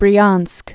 (brē-änsk)